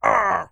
Vampire_Hurt2.wav